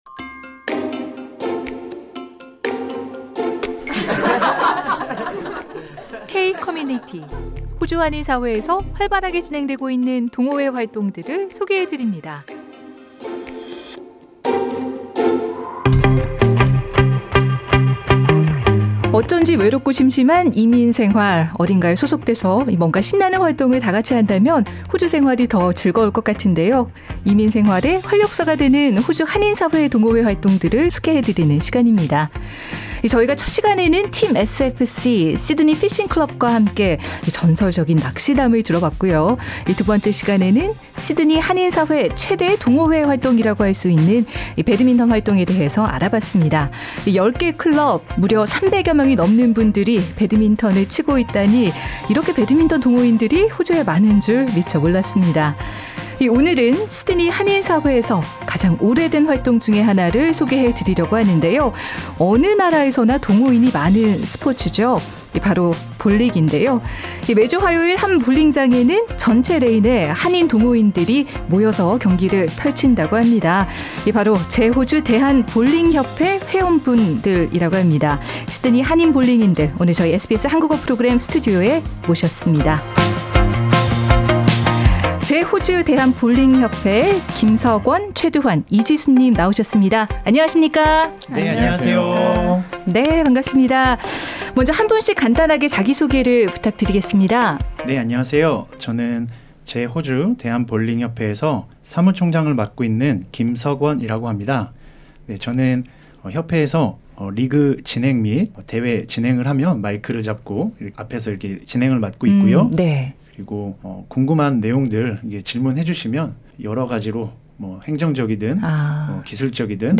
시드니 한인 볼링인들 오늘 저희 SBS 한국어 프로그램 스튜디오에 모셨습니다.